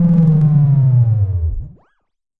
描述：与"Attack Zound03"相似，但音调较低。这个声音是用Cubase SX中的Waldorf Attack VSTi制作的。
Tag: 电子 SoundEffect中